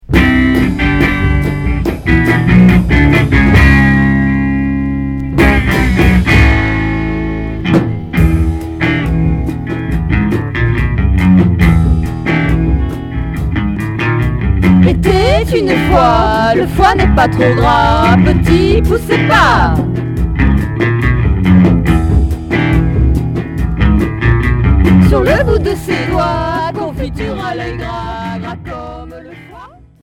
Pop rock dada